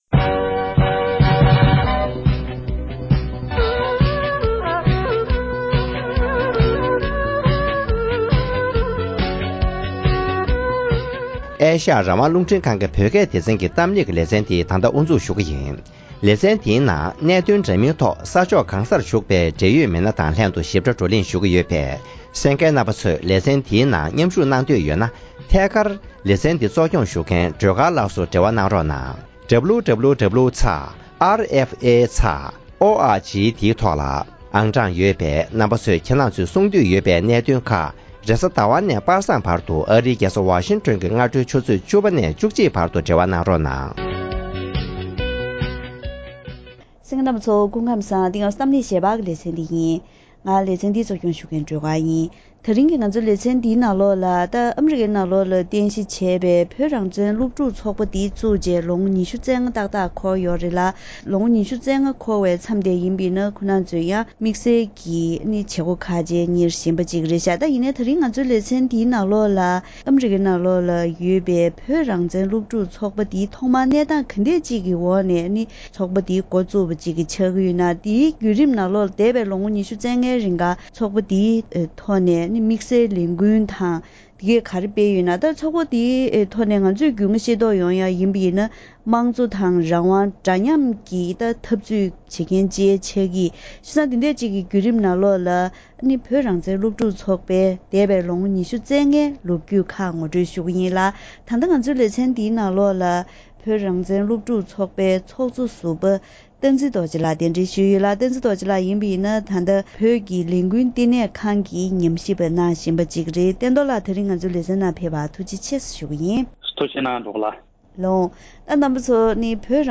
དེ་རིང་གི་གཏམ་གླེང་ཞལ་པར་ལེ་ཚན་ནང་རང་དབང་དང་དམངས་གཙོ།འདྲ་མཉམ་གཞི་རྩར་བཟུང་པའི་ཐོག་སྤྱི་ལོ་༡༩༩༤ལོར་ཨ་རིའི་ནང་གསར་བཙུགས་གནང་པའི་བོད་རང་བཙན་སློབ་ཕྲུག་ཚོགས་པ་ནས་འདས་པའི་ལོ་ངོ་༢༥རིང་གི་སྤྱི་ཡོངས་ཀྱི་ལས་དོན་དང་ཐོག་མར་ཨ་རིའི་གཞོན་སྐྱེས་ཀྱི་ཁྲོད་བོད་དོན་ངོ་སྤྲོད་དང་ལས་འགུལ་ཇི་ལྟར་སྤེལ་ཡོད་པའི་སྐོར་ལ་འབྲེལ་ཡོད་དང་བཀའ་མོལ་ཞུས་པ་ཞིག་གསན་རོགས་གནང་།